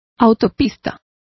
Complete with pronunciation of the translation of highway.